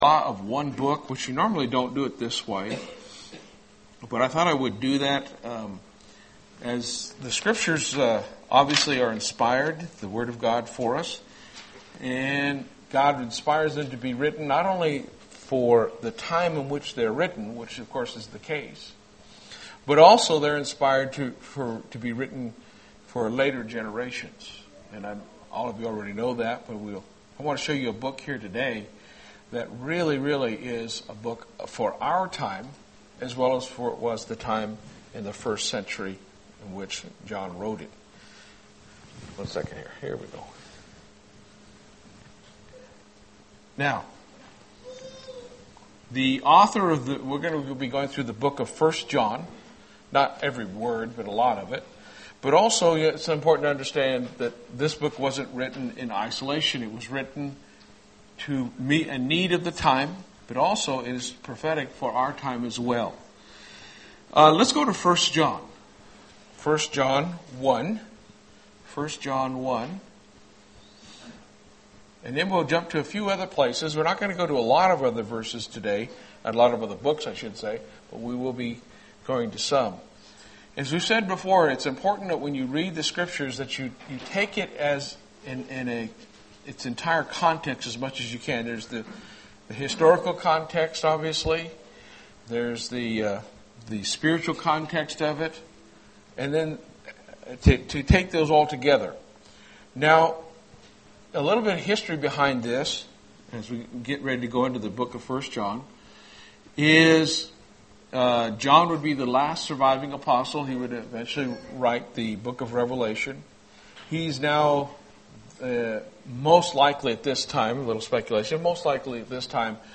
Given in Lubbock, TX
UCG Sermon Studying the bible?